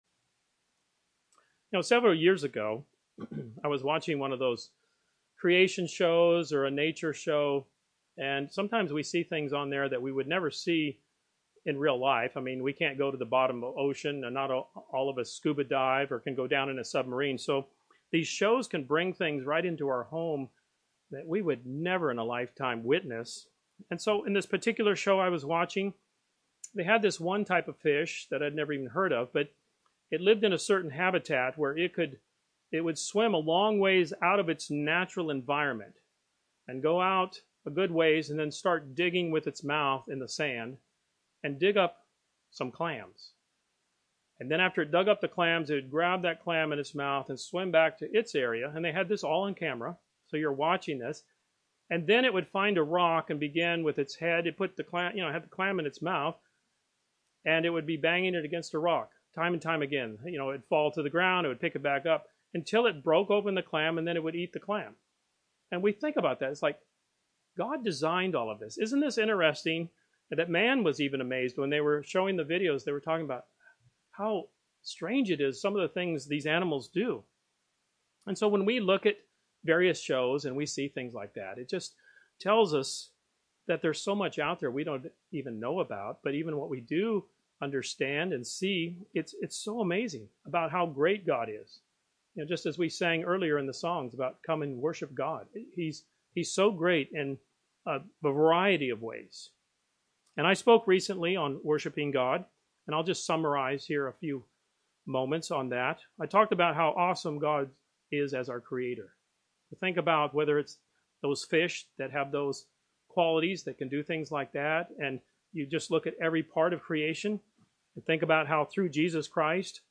This sermon touches on several aspects of why we should improve our worship of God.
Given in Tampa, FL